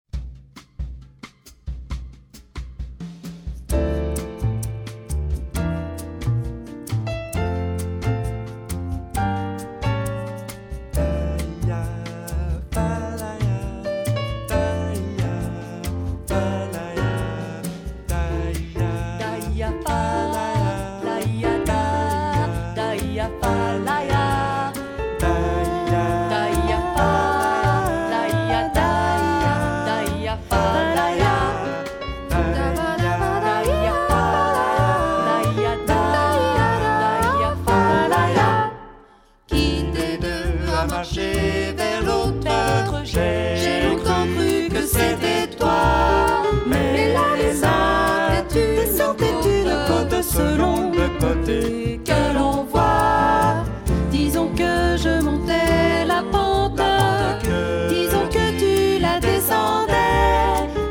15 tracks arranged for mixed choir and jazz trio